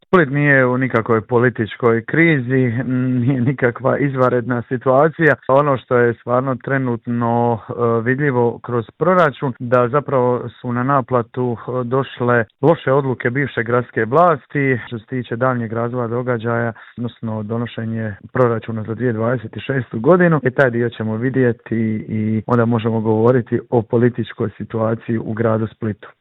U Intervju Media servisa razgovarali smo s gradonačelnikom Splita Tomislavom Šutom koji nam je prokomentirao aktualnu situaciju i otkrio je li spreman za eventualne izvanredne izbore.